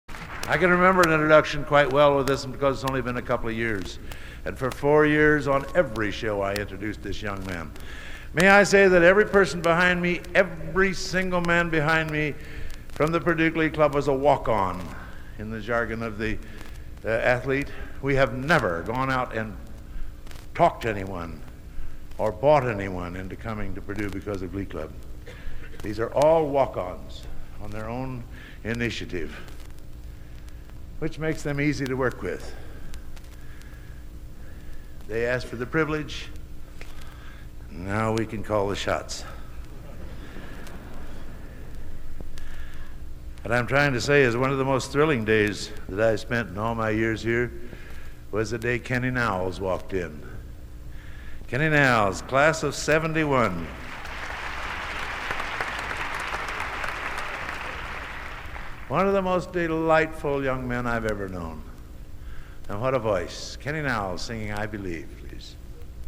Era: 1970s
Genre: | Type: Director intros, emceeing